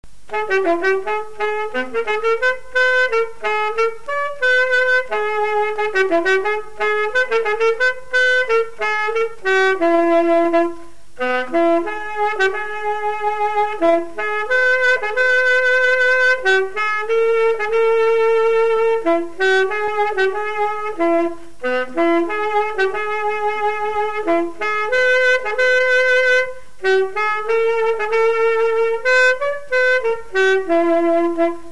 Résumé instrumental
Pièce musicale inédite